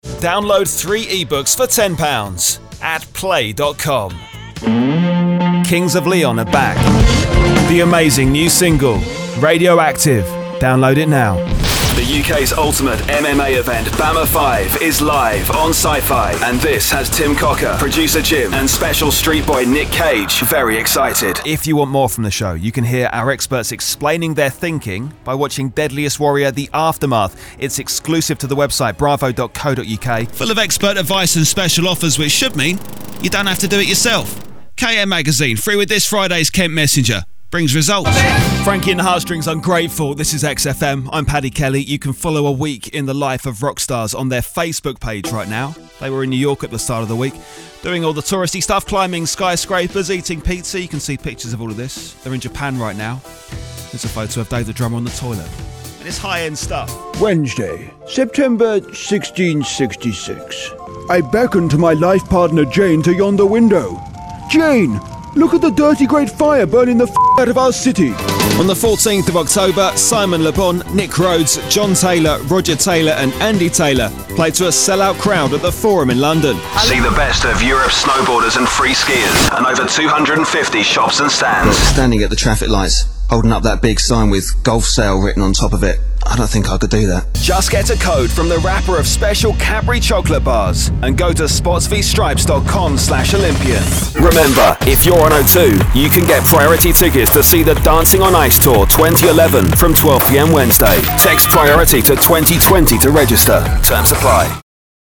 From cool & credible to energetic sports commentator. A hip, young sell for your product.